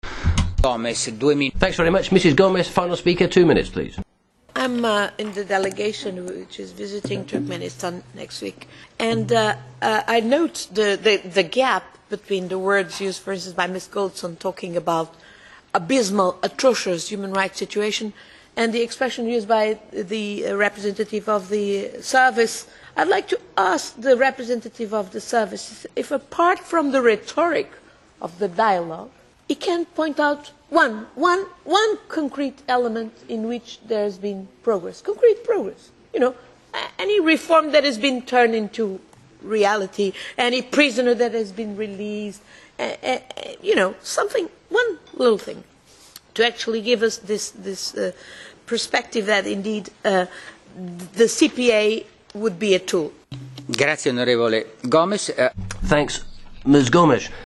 Ana Gomeşiň deňlenişikdäki çykyşyndan bölek (20-nji aprel, Brýussel)